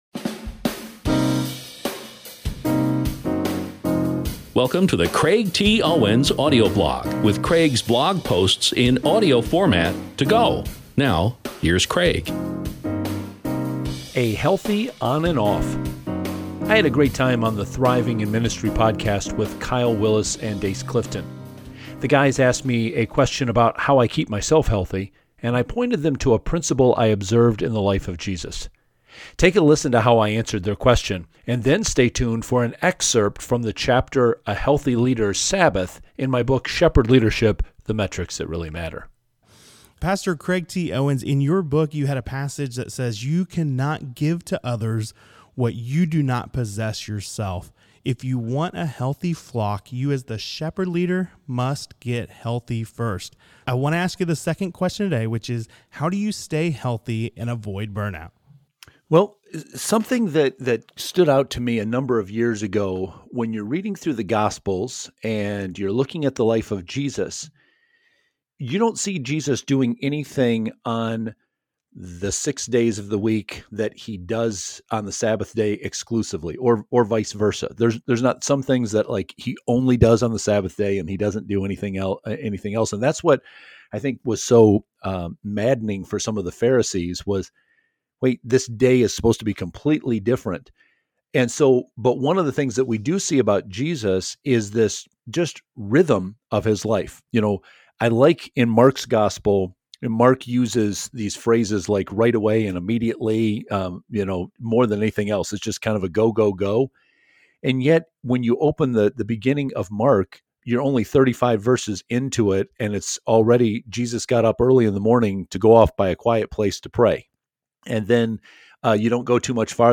I’ll be sharing more clips from this Thriving In Ministry interview soon, so please stay tuned.